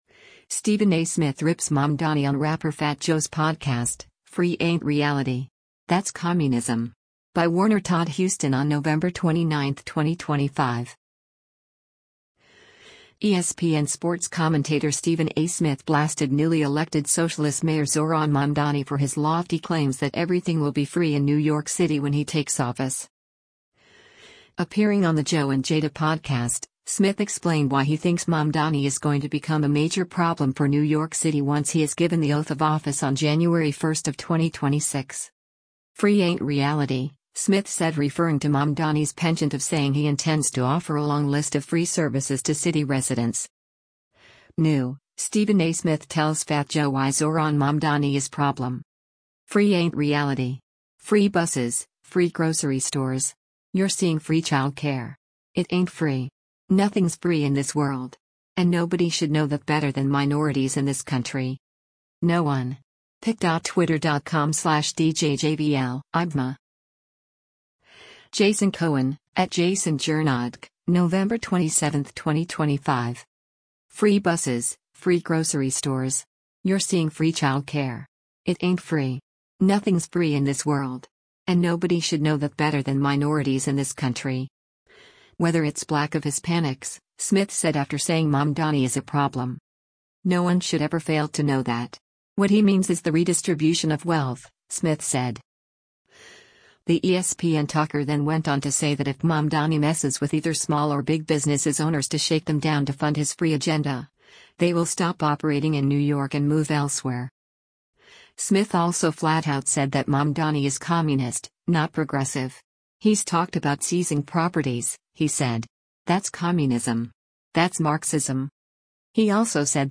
Stephen A. Smith Rips Mamdani on Rapper Fat Joe’s Podcast: ‘Free Ain’t Reality… That’s Communism’